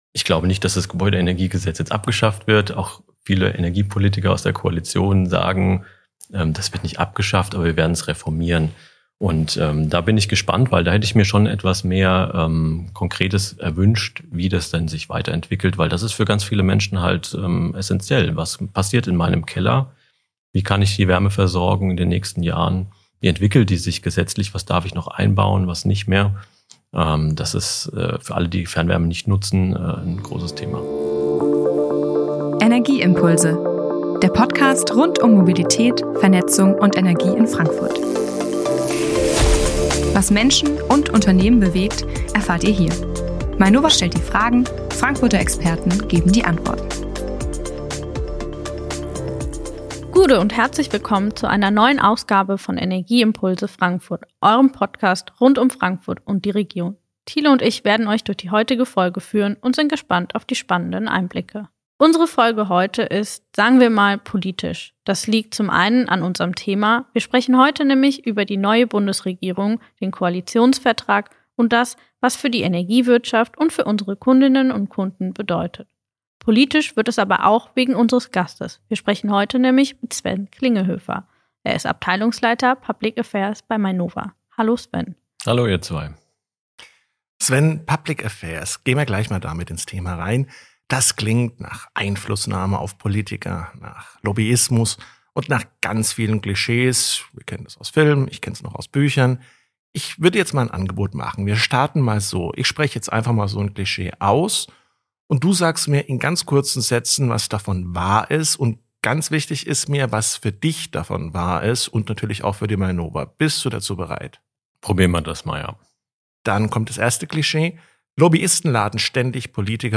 Welche Rolle spielt Wasserstoff für die neue Regierung? Und wie wirken sich neue Rahmenbedingungen auf unsere Energie für Frankfurt aus? Antworten gibt’s im Gespräch – kompakt, klar und kritisch.